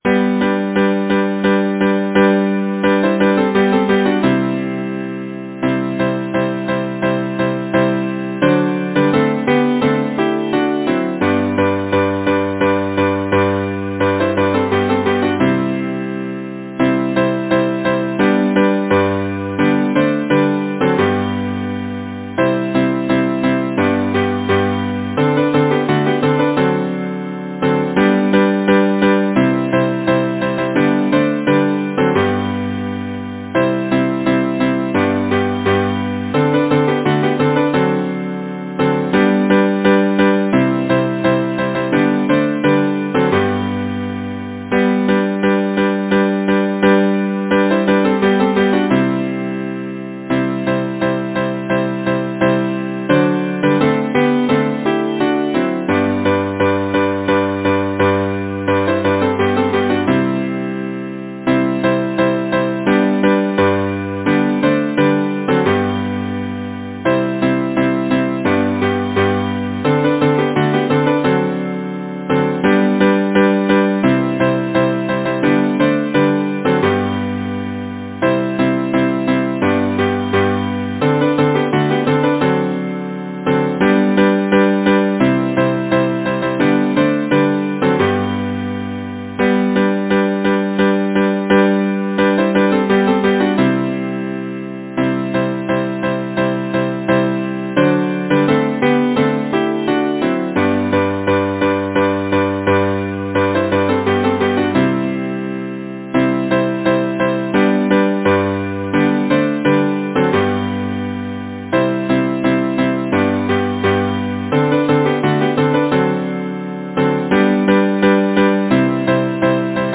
Title: The Water Mill Composer: Charles Clinton Case Lyricist: Sarah Doudney Number of voices: 4vv Voicing: SATB Genre: Secular, Partsong
Language: English Instruments: A cappella